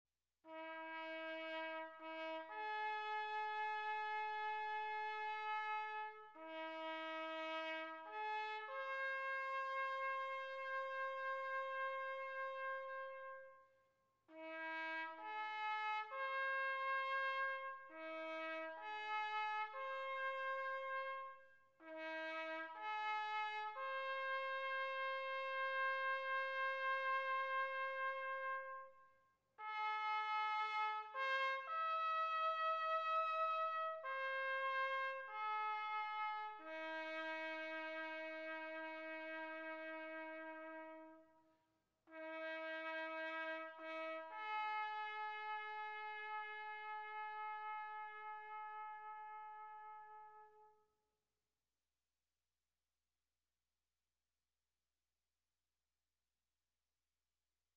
USCG Bugler Playing Taps.mp3